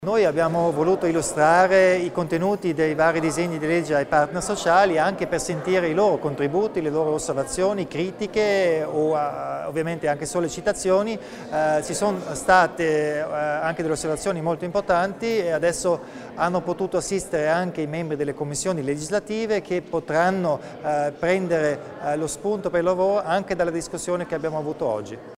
Il Presidente Kompatscher spiega l'importanza dell'incontro con le parti sociali